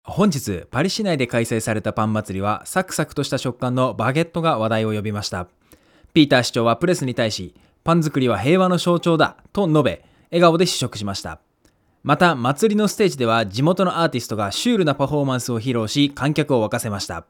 音声収録は全てH6 Essentialに同時録音をしたので読み上げの誤差は全く無い状態だ。
はっきり言ってポンコツ耳の僕だが､少なくともShure MV7･Sennheiser MKE600･FIFINE Amplitank K688これら3つの音声の違いはほぼ感じられなかった。
【マイク4(Sennheiser MKE600)】